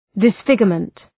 Shkrimi fonetik{dıs’fıgjərmənt}
disfigurement.mp3